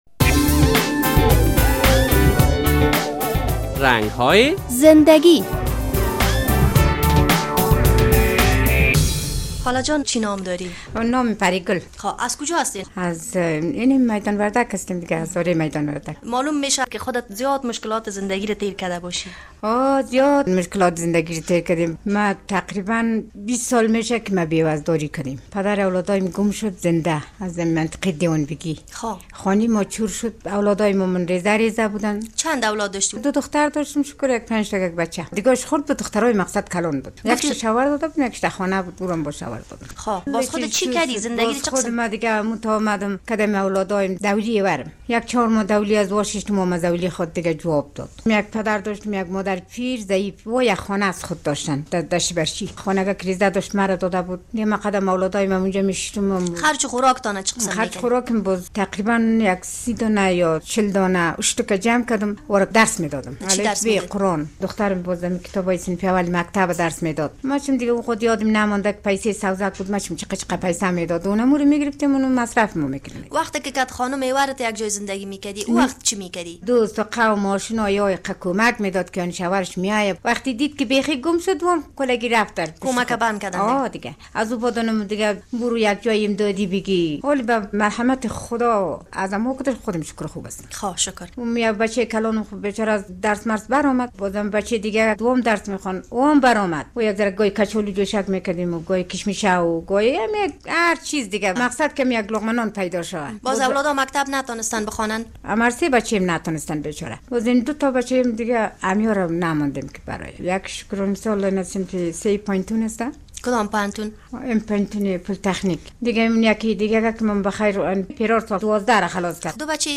در این برنامهء رنگ های زنده گی با یک خانم صحبت شده است.